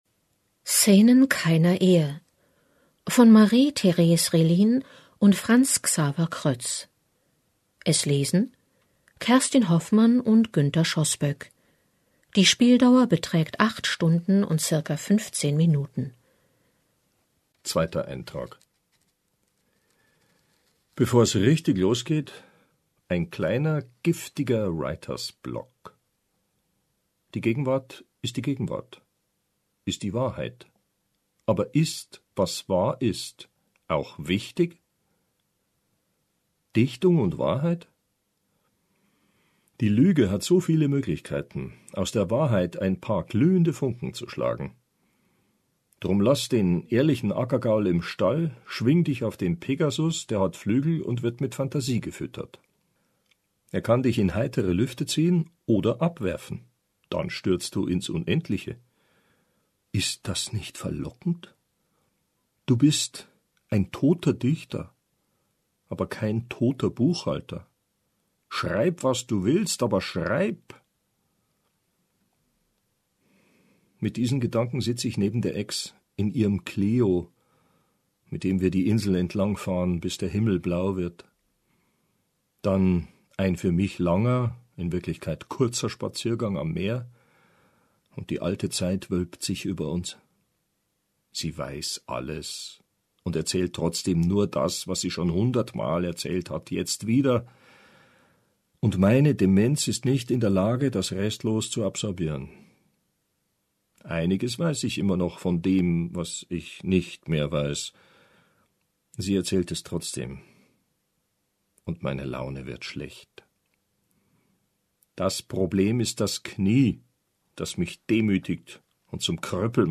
liest diese Gedanken eines ehemaligen Ehepaares über eine besondere Zeit: